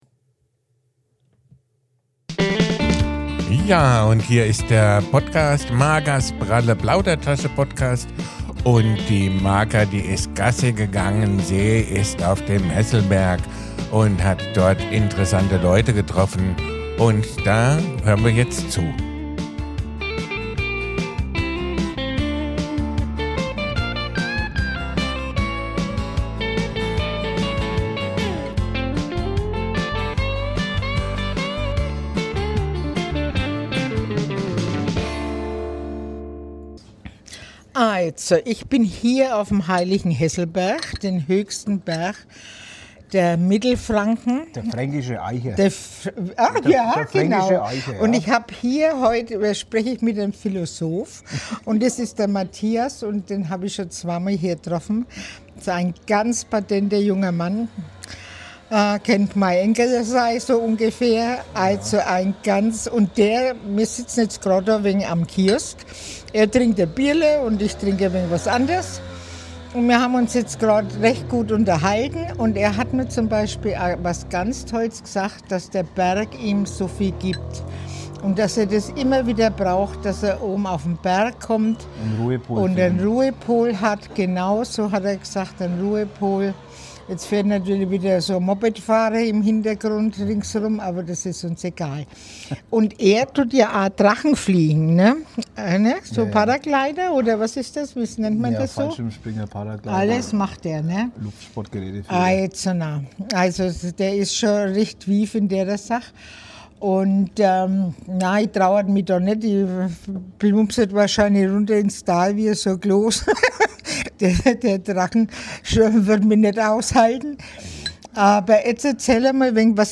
Ein Gespräch über Ruhe, Drachenfliegen und den Zauber des höchsten fränkischen Gipfels.